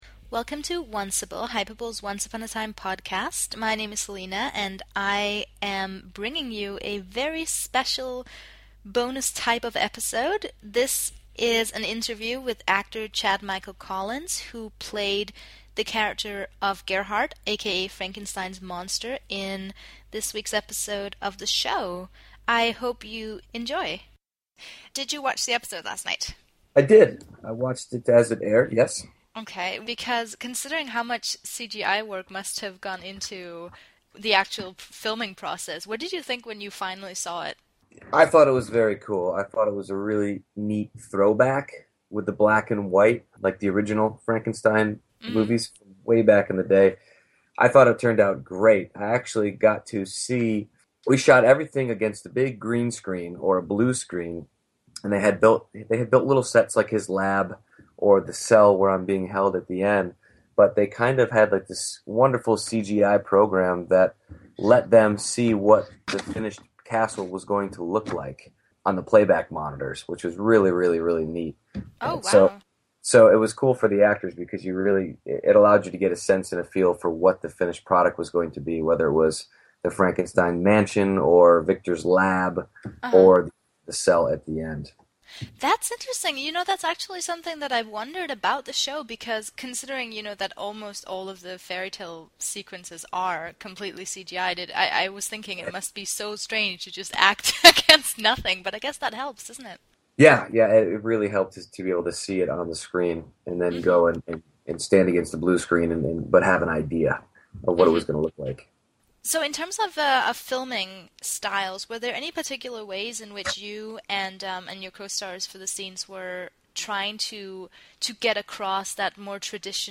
Onceable Bonus Episode: Exclusive Interview with 'Once Upon a Time' guest star Chad Michael Collins (Frankenstein's Monster)